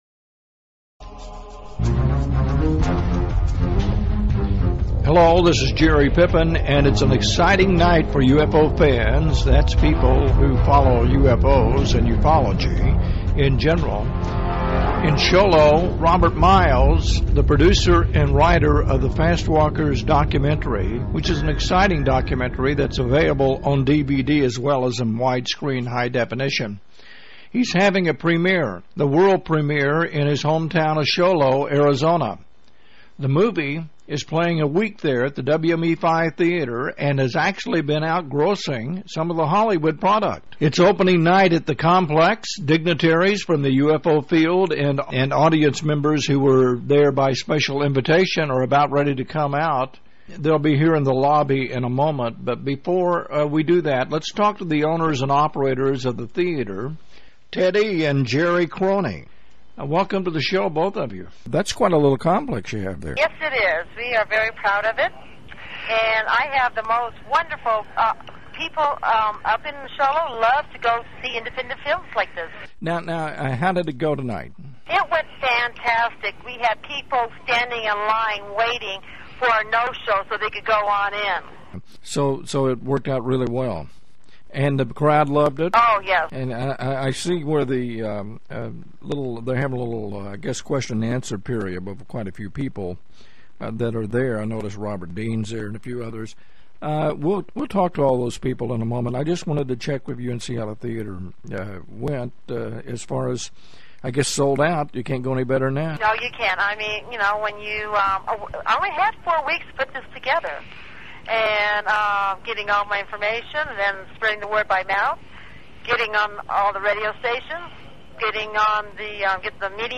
Fastwalkers Premiere Interviews.mp3